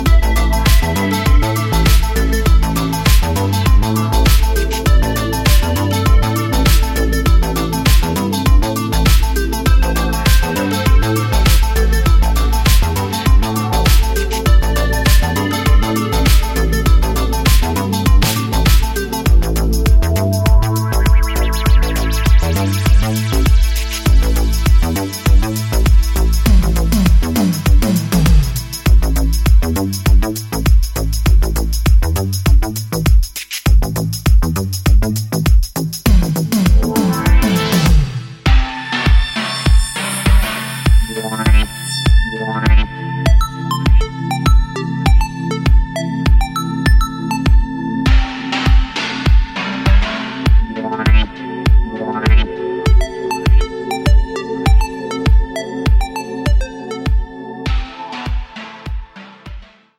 スローテンポでレトロフューチャーなムードを醸す
コミカルな80sサウンドをモダンなセンスで昇華した、ナイスな1枚です！